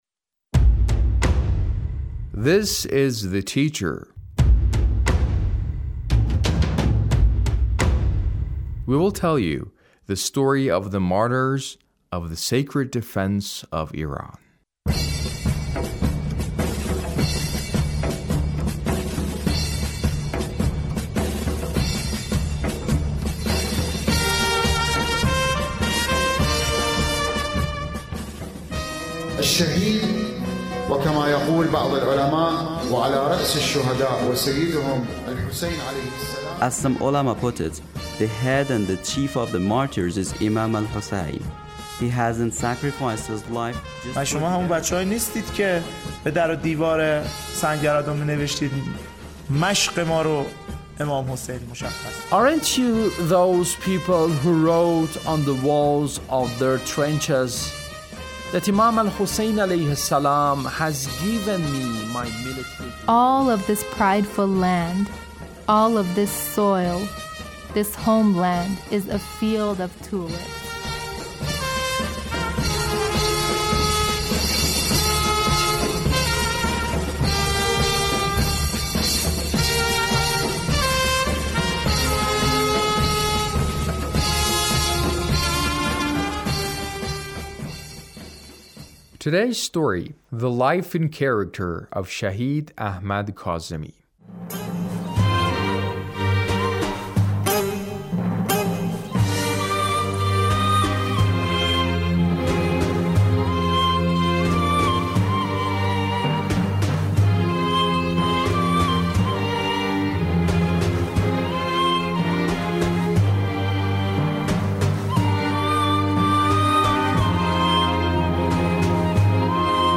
A radio documentary on the life of Shahid Ahmad Kazemi- Part 3